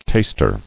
[tast·er]